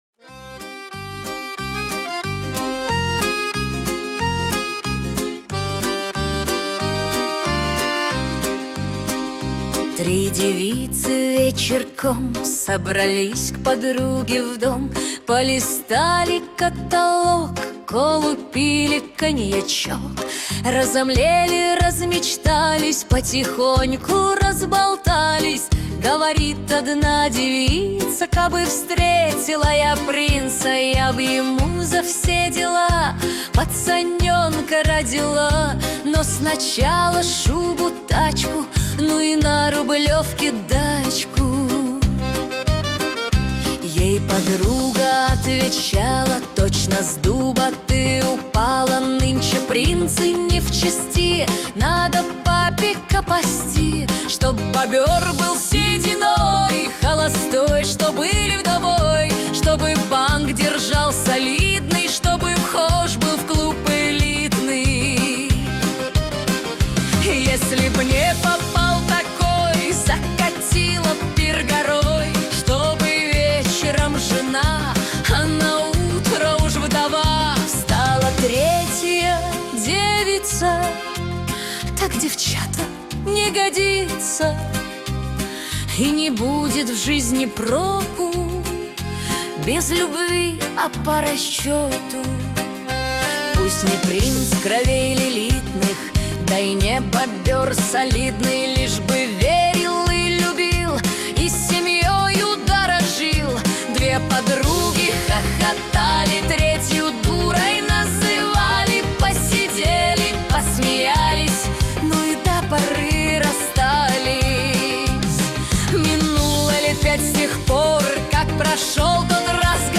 Нейросеть Песни 2025